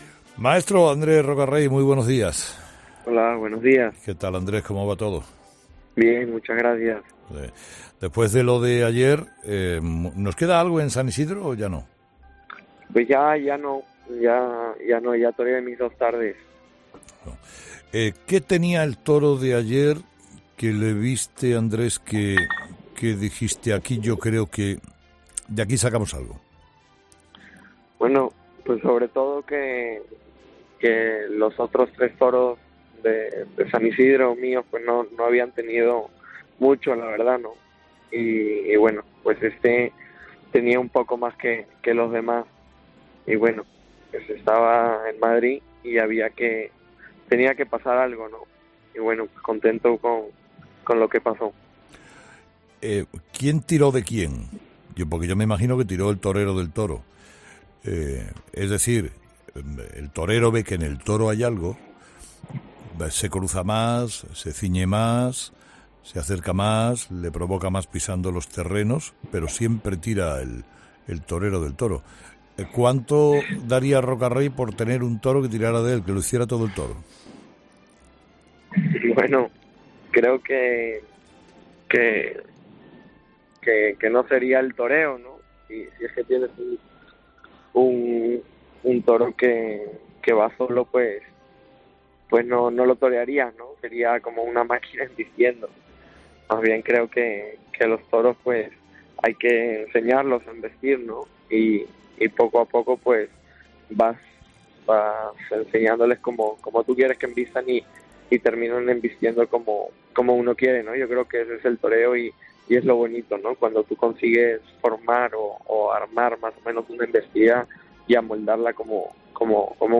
El diestro peruano Andrés Roca Rey habla con Carlos Herrera de su actuación este miércoles en la Feria de San Isidro.
Escucha la entrevista a Andrés Roca Rey en Herrera en COPE